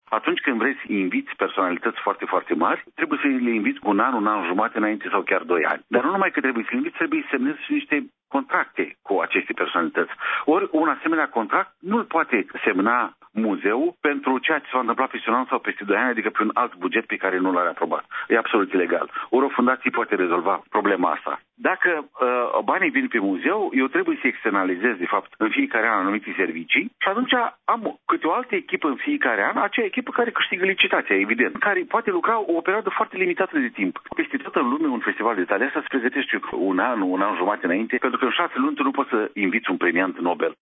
Într-o declaraţie acordată postului nostru de radio, scriitorul a prezentat principalele avantaje ale gestionării acestui festival cu ajutorul unei fundaţii şi nu prin intermediul Muzeului Literaturii Române din Iaşi: